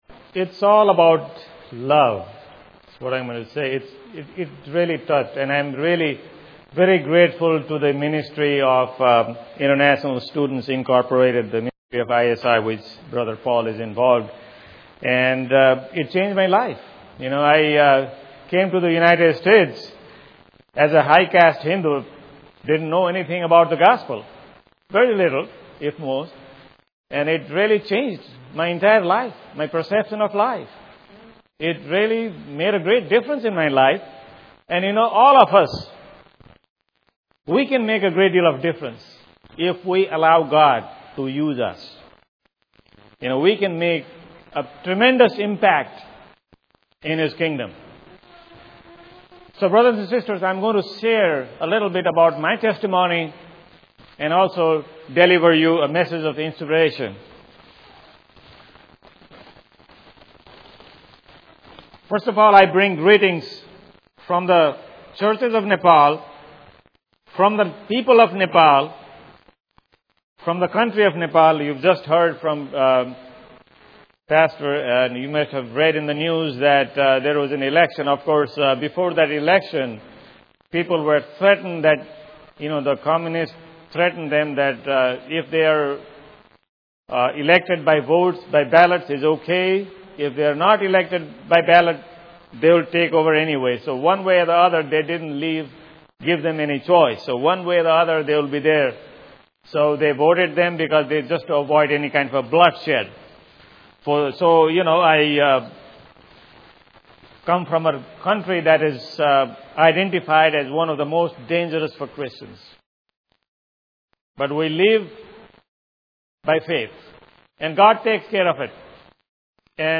A testimony from the mission field.